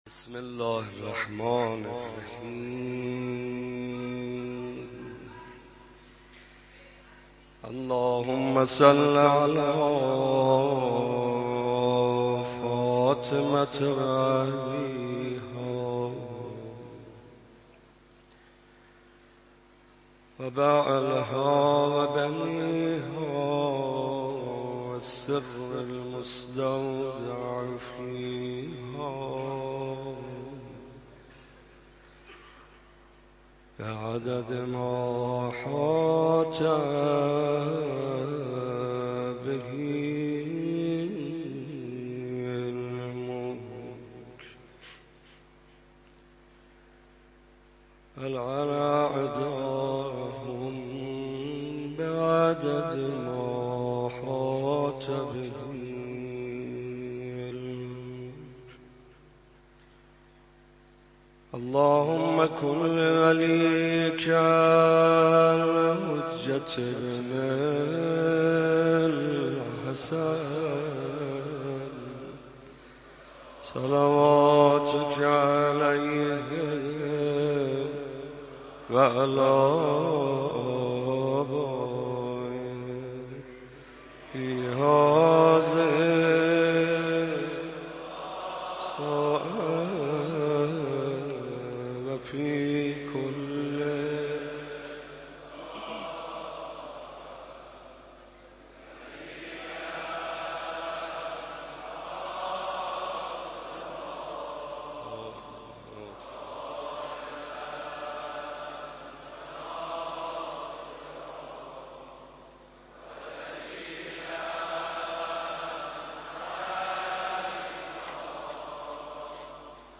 مداحی طاهری در دومین شب فاطمیه - تسنیم
مداحی حاج محمدرضا طاهری در دومین شب عزاداری فاطمیه 1438را با حضور رهبر معظم انقلاب در حسینیه امام خمینی (ره) می‌شنوید.